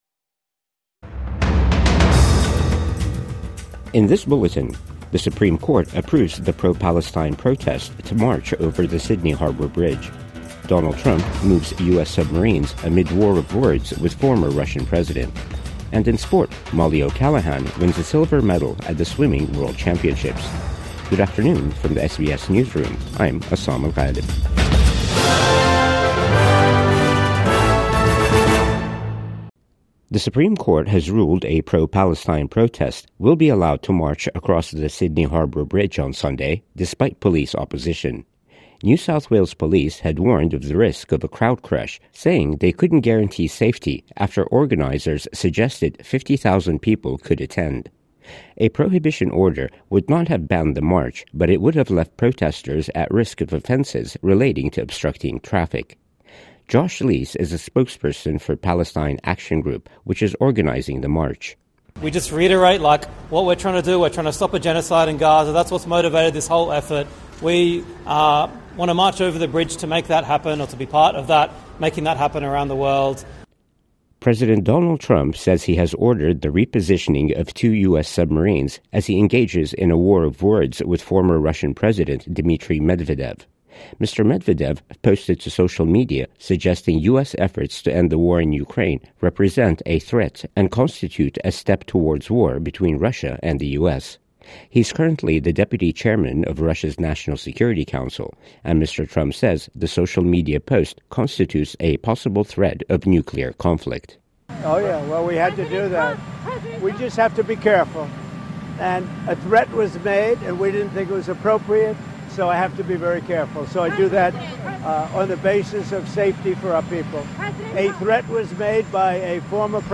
Supreme Court approves pro-Palestine Harbour Bridge march | Midday News Bulletin 2 August 2025